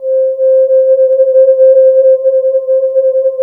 13 JX VOICES.wav